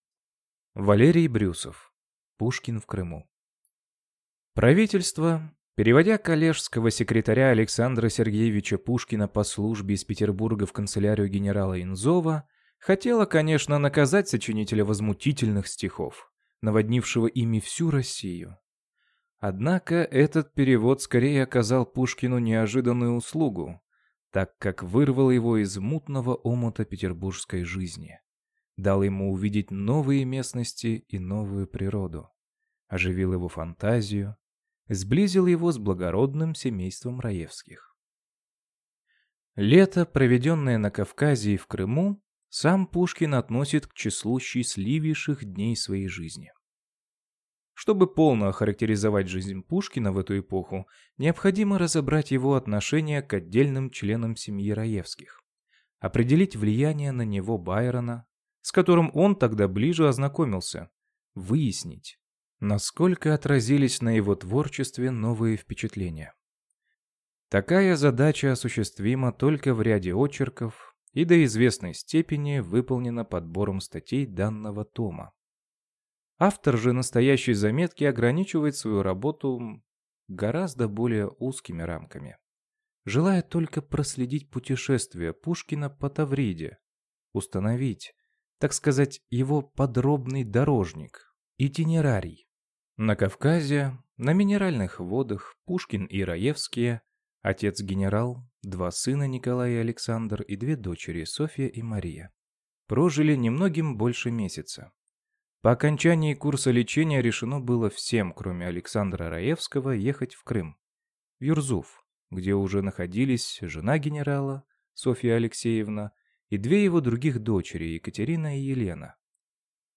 Аудиокнига Пушкин в Крыму | Библиотека аудиокниг